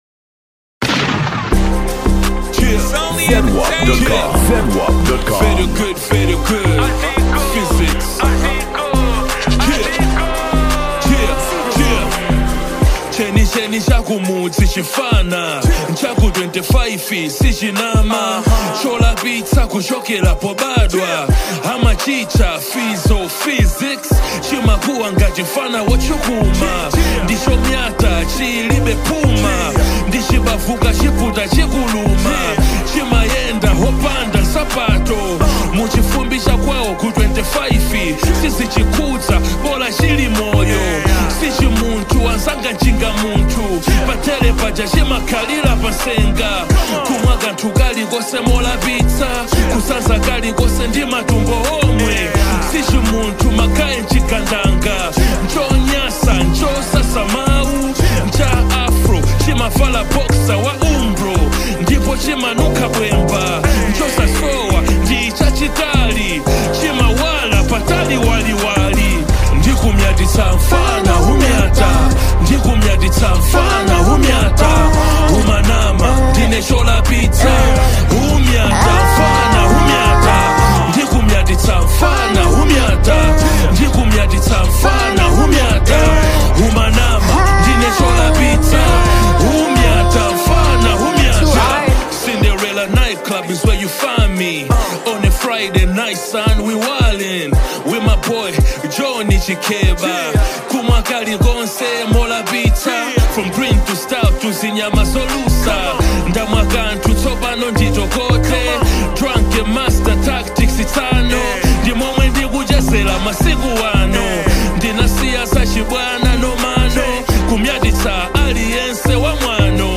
Género musical: Afro Beat